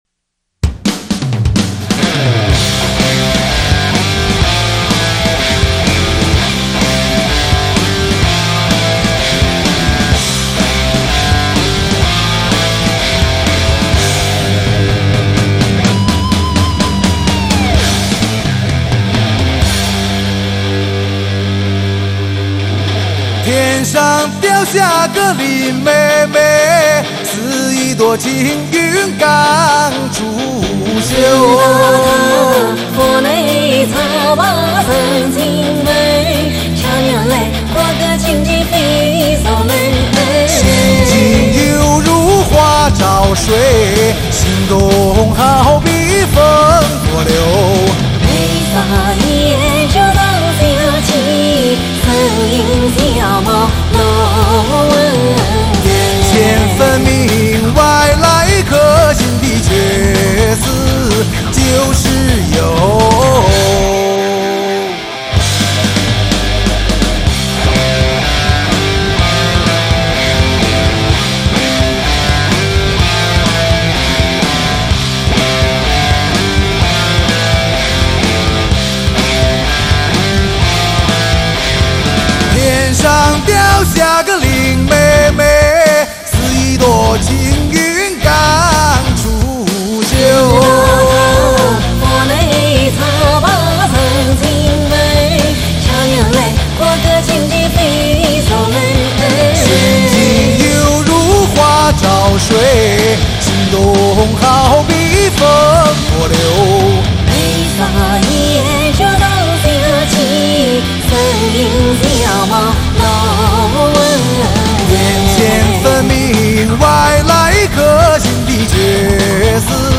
越剧